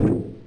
mech_walk_1.ogg